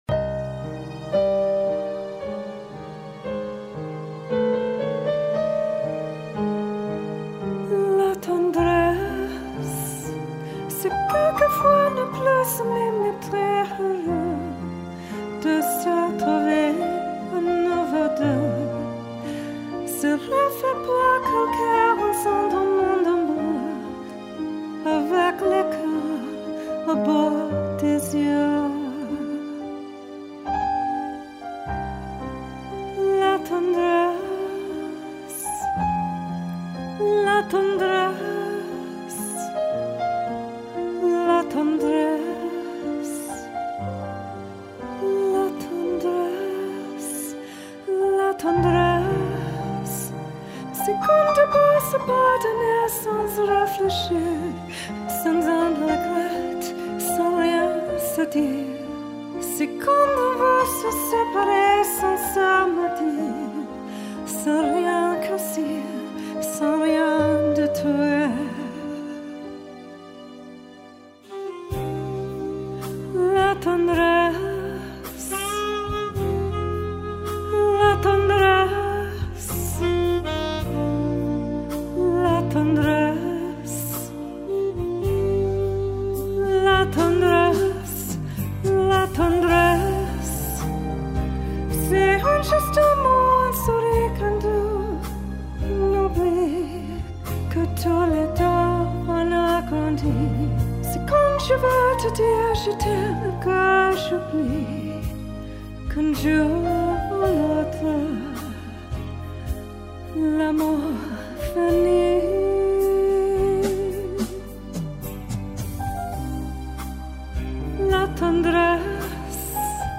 Demo tracks recorded & mixed in Vancouver, BC Canada at:
Studio Recorded October, 2017